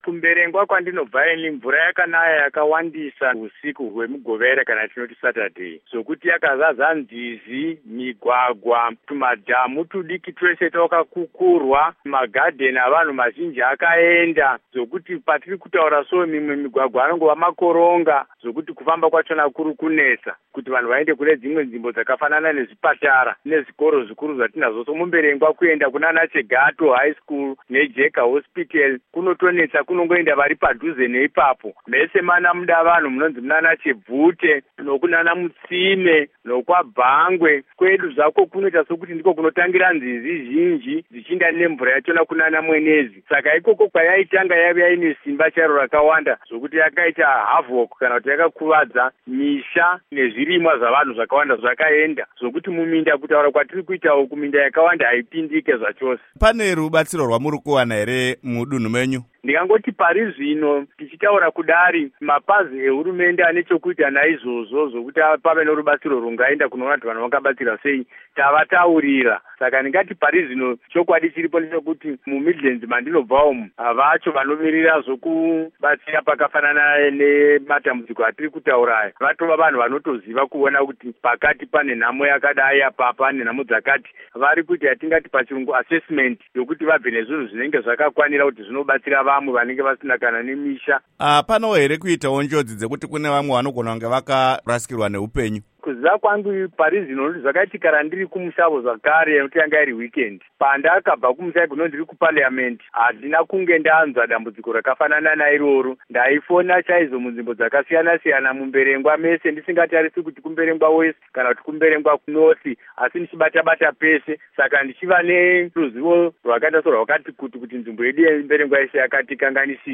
Hurukuro naVaJoram Gumbo